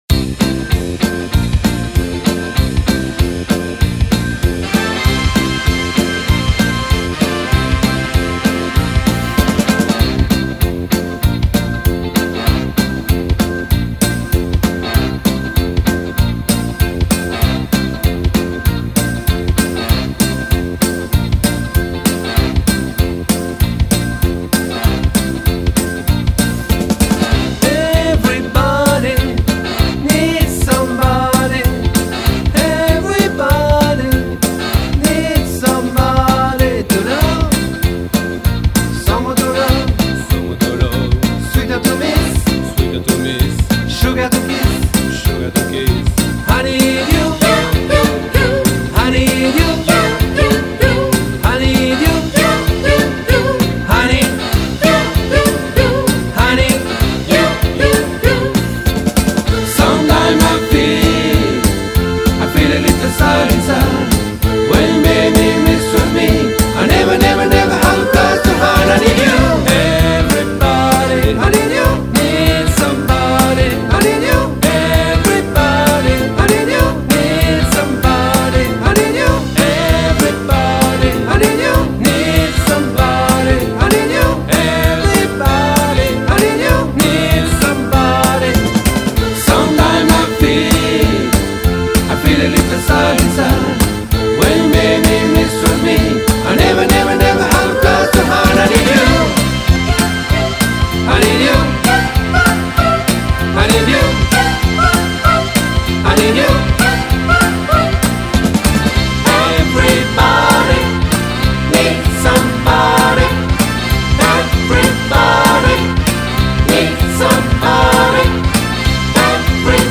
Tutti